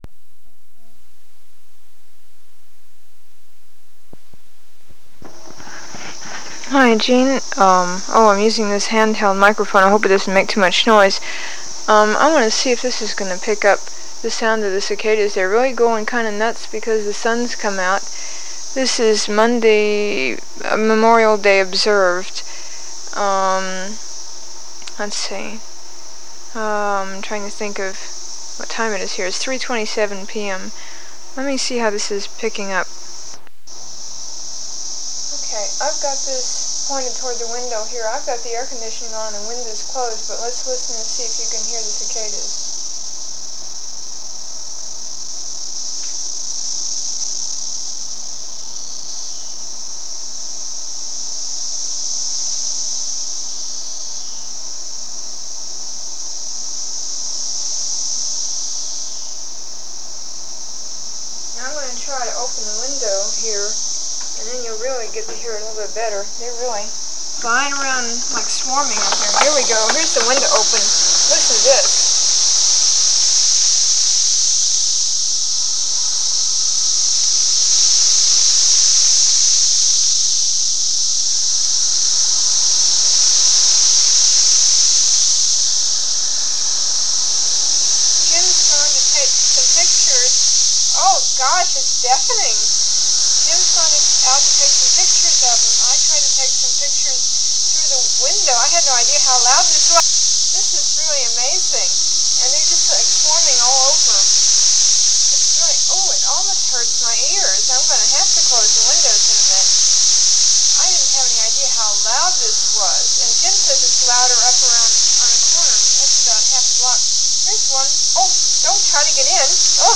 Warning: This is cicada sound, but with a LOT of commentary.
CICADA SOUND FROM 1987
~~ (this is basically just LOUD) ~~
These are all from one cassette tape I made in late May 1987 from inside our apartment here in Cincinnati, Ohio.